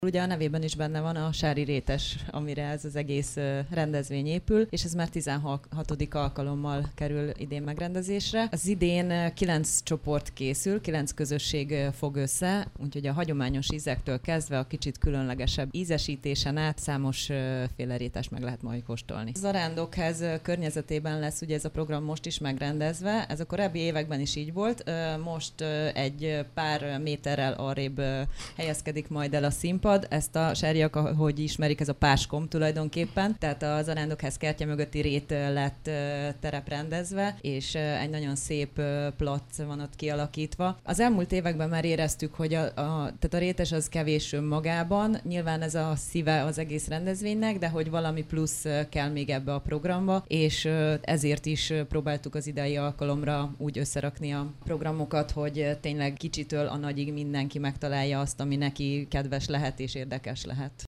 Korábban egy dugulás okozott csatornaproblémákat több utcában, illetve a nyári időszakban az ivóvíz minőségére vannak lakossági panaszok. A testület célul tűzte ki, hogy jelentős költségráfordítással elvégezteti a szivacsos csőtisztítást, illetve a tolózárakat beépítteti a rendszerbe. Hajnal Csilla polgármestert hallják.